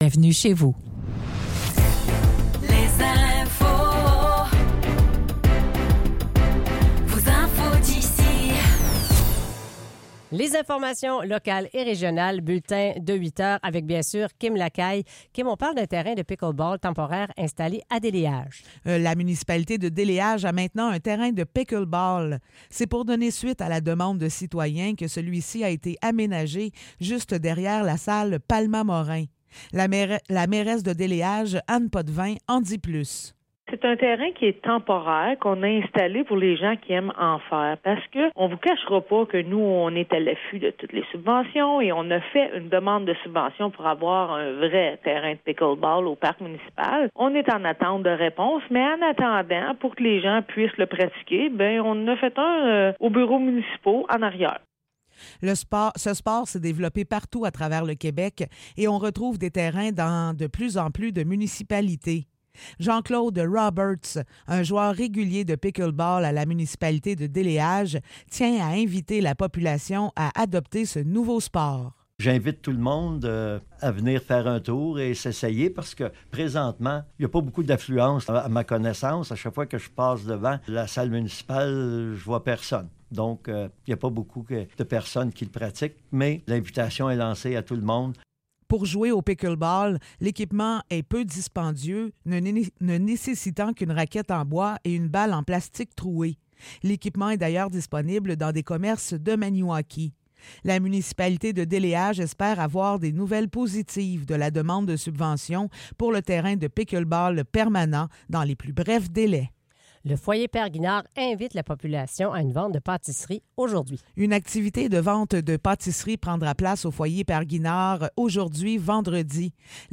Nouvelles locales - 26 juillet 2024 - 8 h